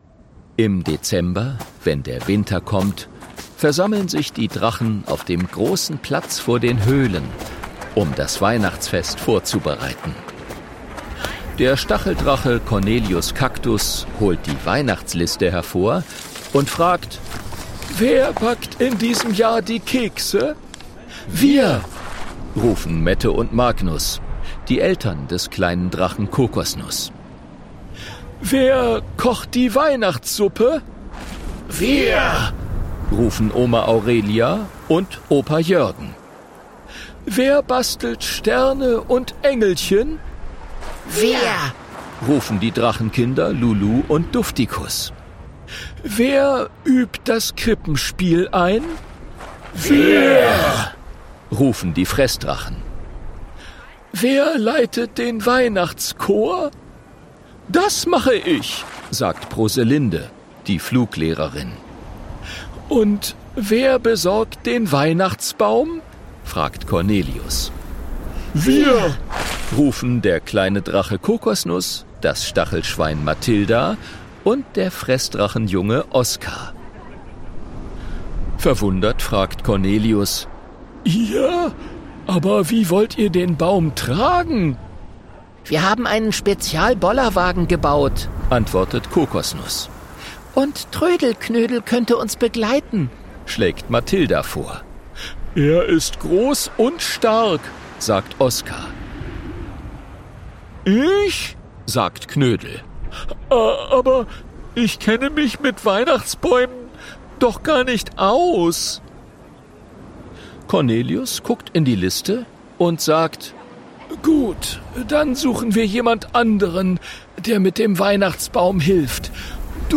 Ungekürzte Lesung, Inszenierte Lesung mit Musik